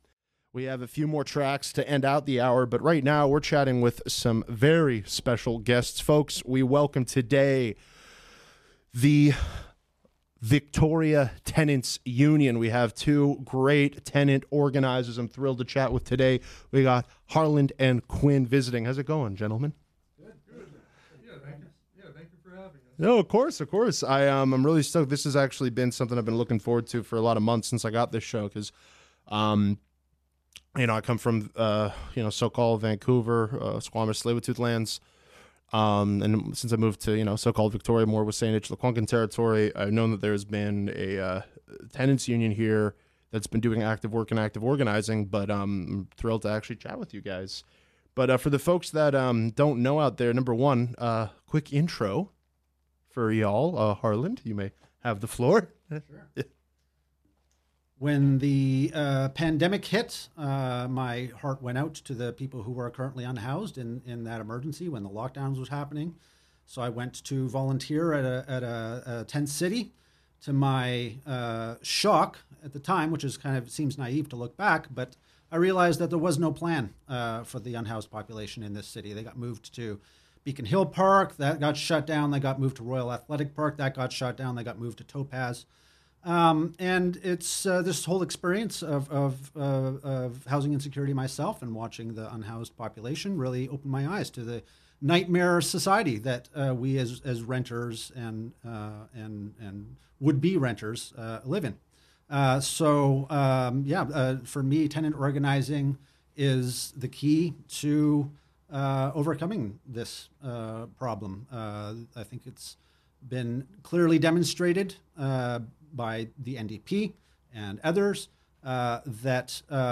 VicTU Interview on Crescendo